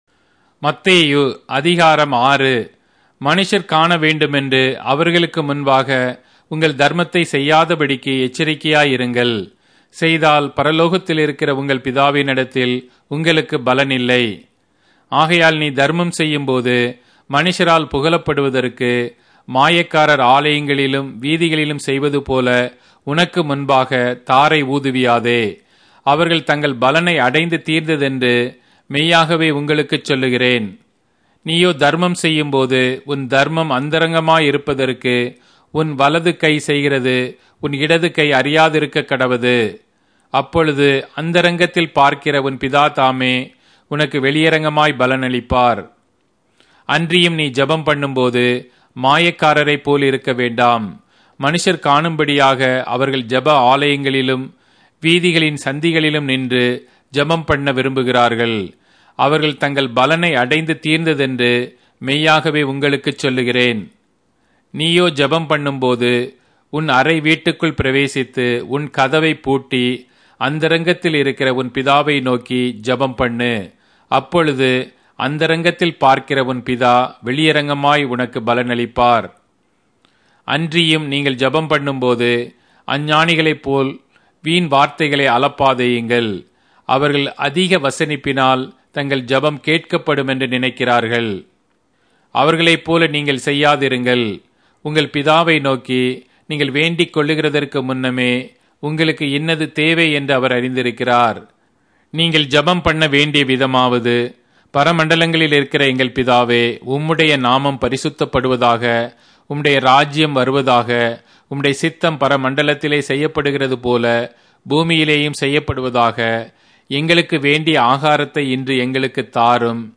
Tamil Audio Bible - Matthew 6 in Irvbn bible version